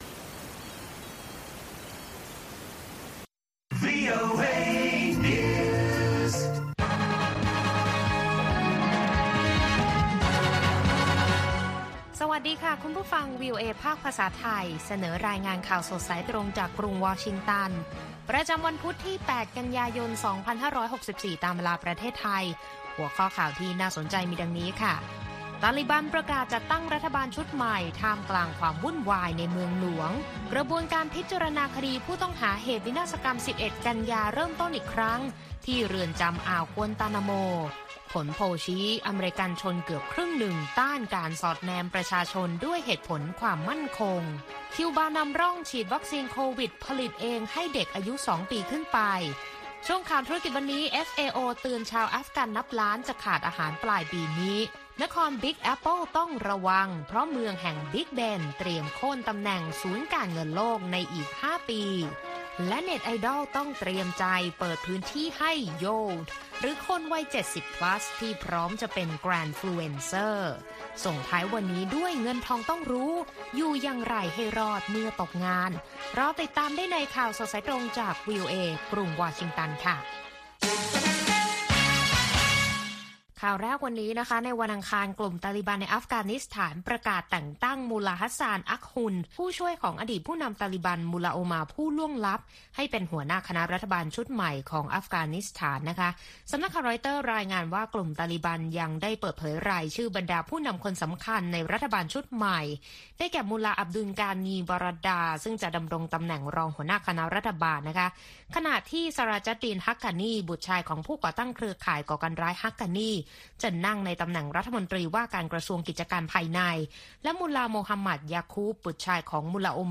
ข่าวสดสายตรงจากวีโอเอ ภาคภาษาไทย ประจำวันพุธที่ 8 กันยายน 2564 ตามเวลาประเทศไทย